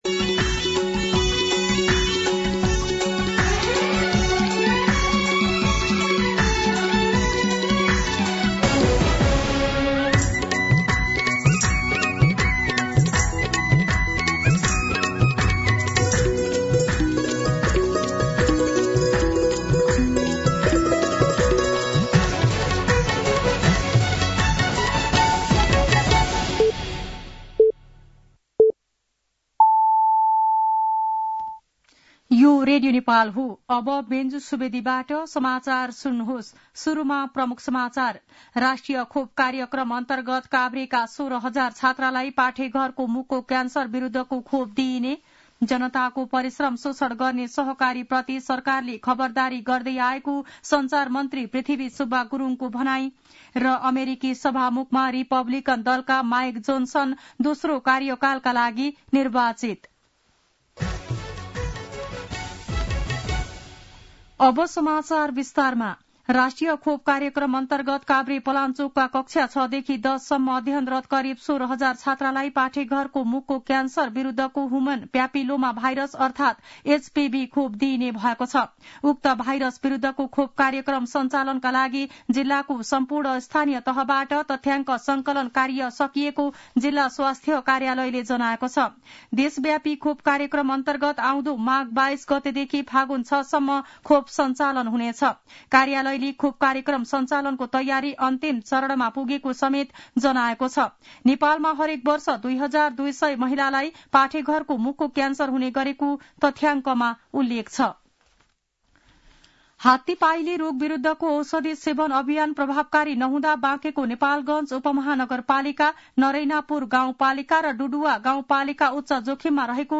दिउँसो ३ बजेको नेपाली समाचार : २१ पुष , २०८१
3-pm-Nepali-News.mp3